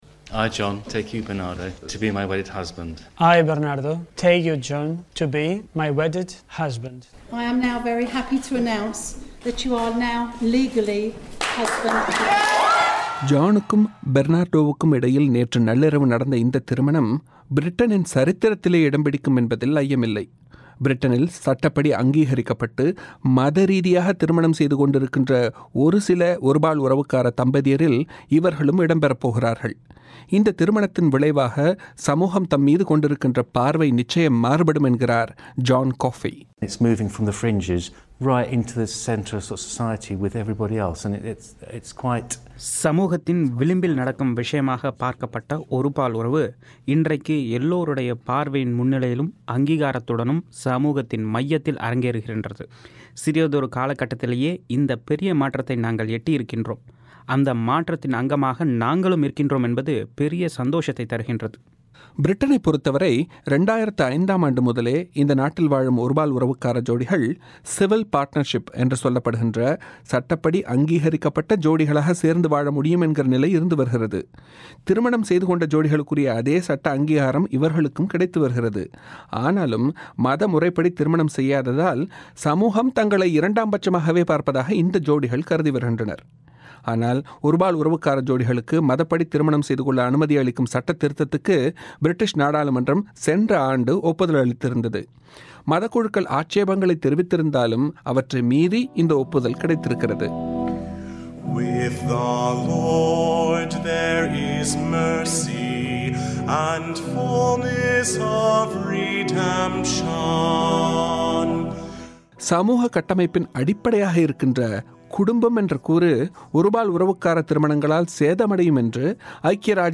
பிரிட்டனில் ஒருபால் உறவுத் திருமணம் - வானொலிப் பெட்டகம்